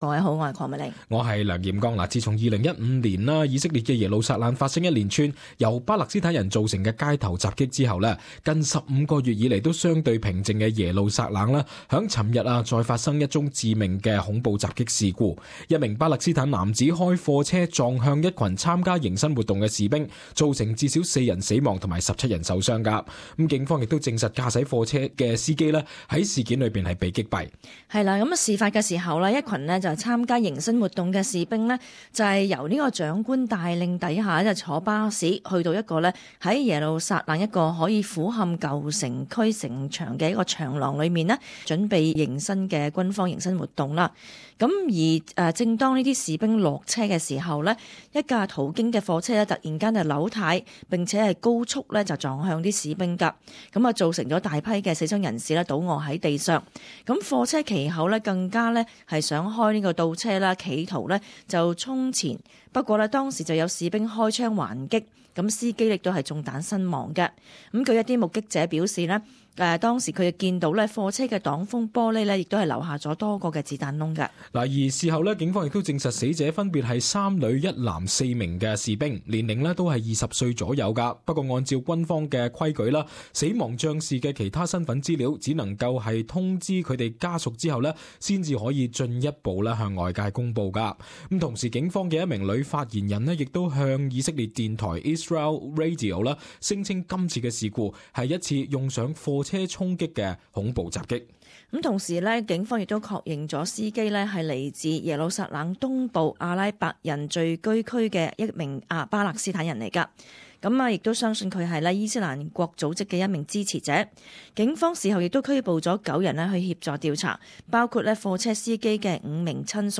【時事報導】以色列警方將耶路撒冷貨車撞死四人案定為恐襲